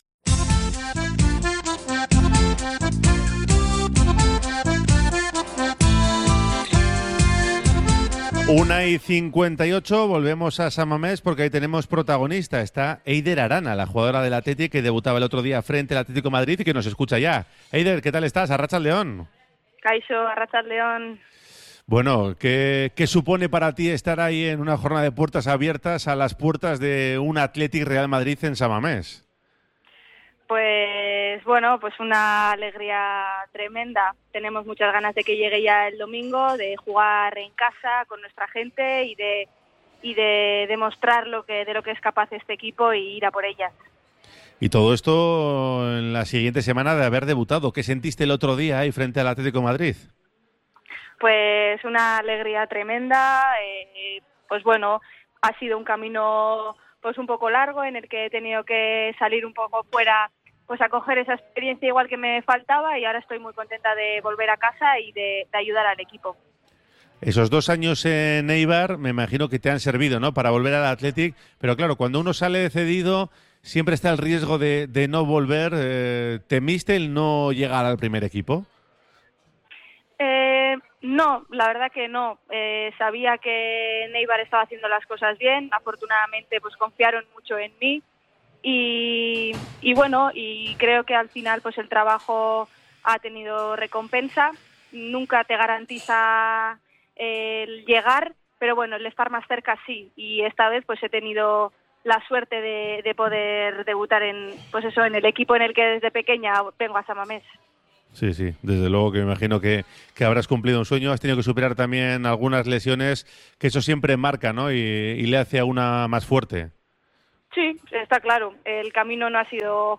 📰 La conversación se ha emitido en el programa Oye Cómo Va de Radio Popular – Herri Irratia.